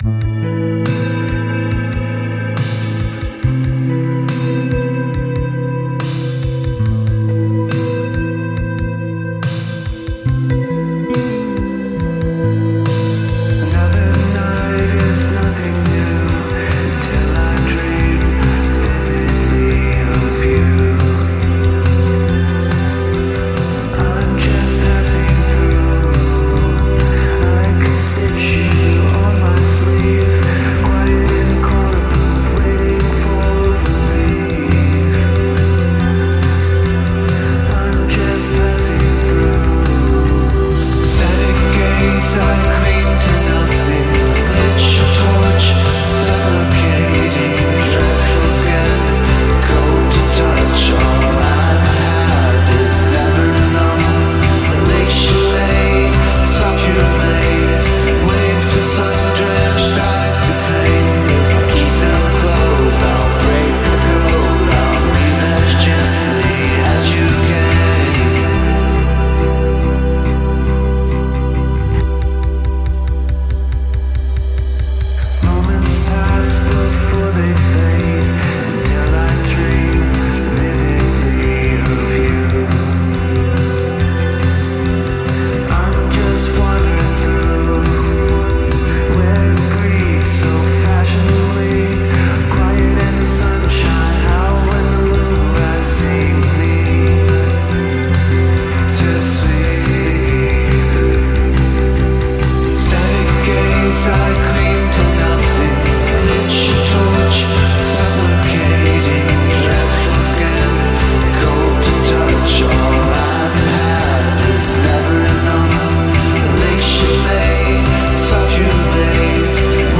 Gothic Rock, Dreampop, Alternative